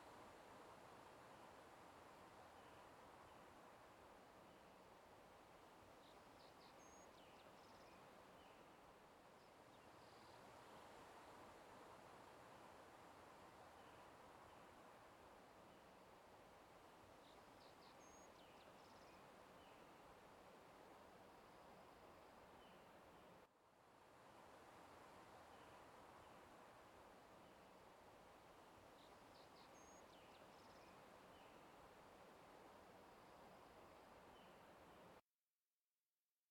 03_书店外黄昏.ogg